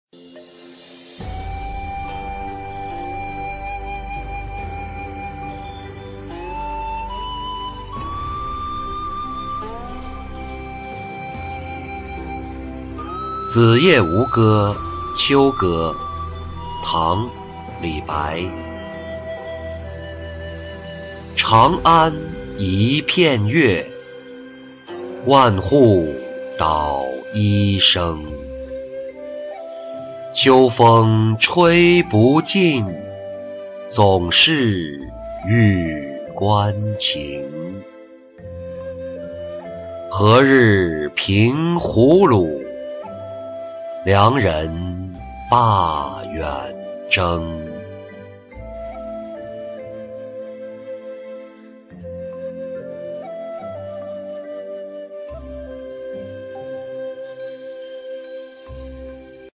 子夜吴歌·秋歌-音频朗读